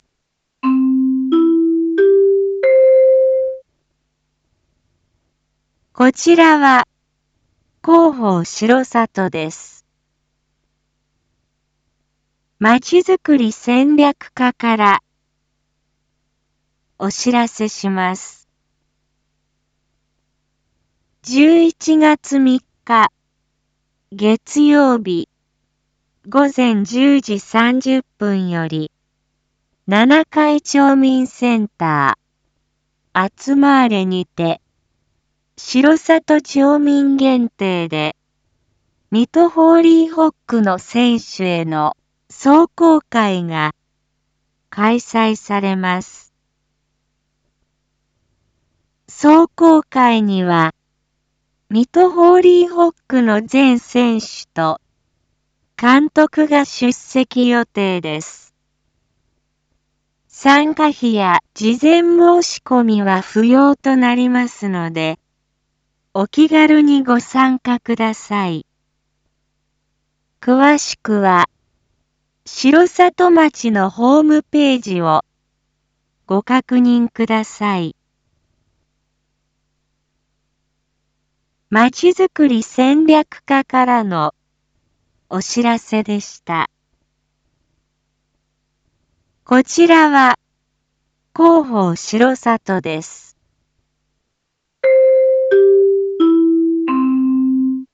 Back Home 一般放送情報 音声放送 再生 一般放送情報 登録日時：2025-10-31 19:01:39 タイトル：水戸ホーリーホック壮行会① インフォメーション：こちらは広報しろさとです。